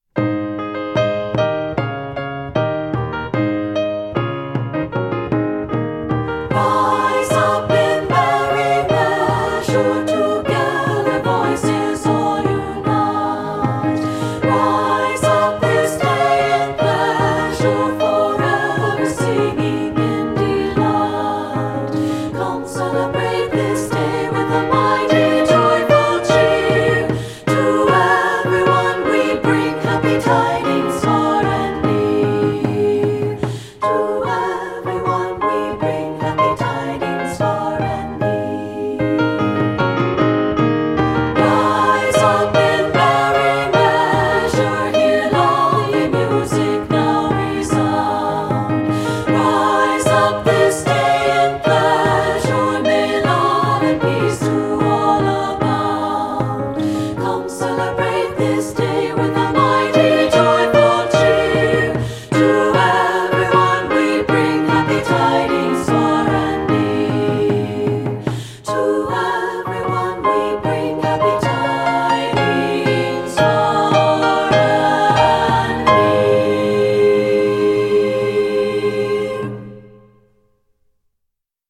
Voicing: SSA a cappella